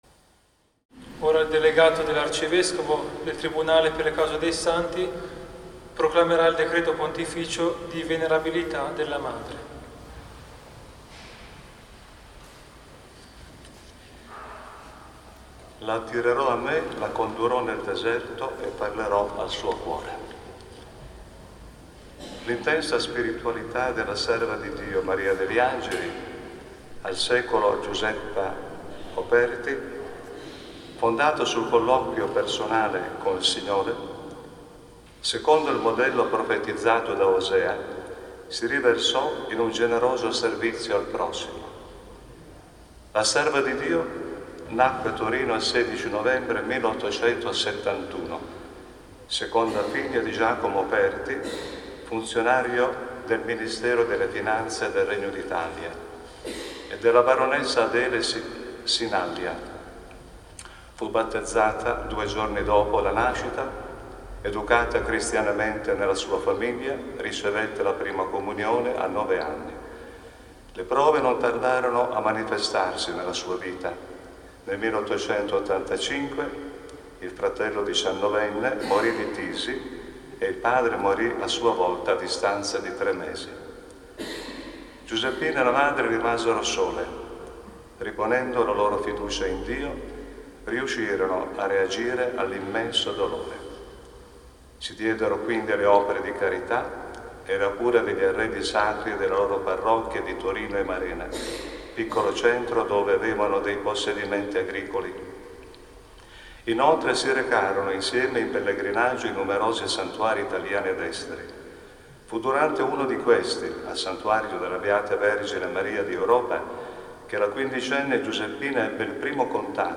Gioia, emozione, profonda gratitudine e un po’ di commozione durante la Celebrazione Eucaristica di sabato 7 ottobre nella Chiesa di S. Teresa a Torino.